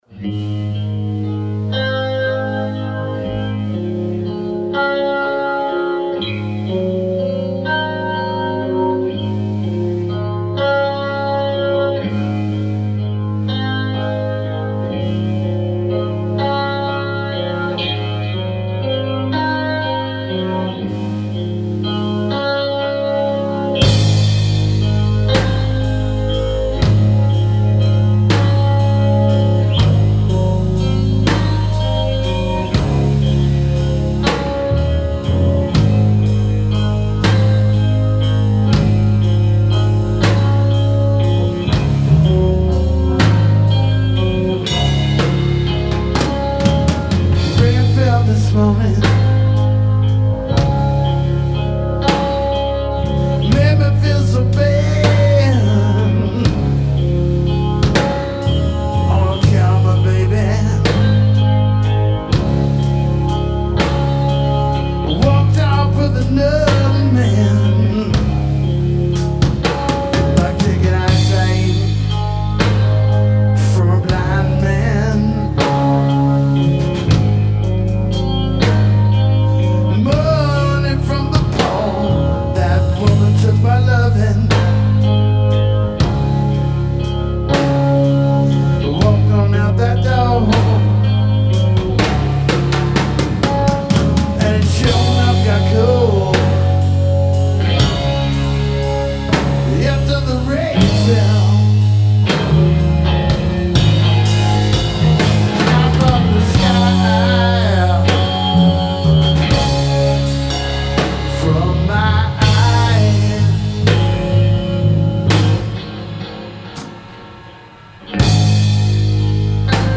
THE LOST WEEKEND: A modern take on the classic “Rock Trio”, Austin music scene veterans playing fun covers (and a few choice originals).
bass, vocals
drums, vocals Musician
guitar, vocals.
Live rehearsal recordings: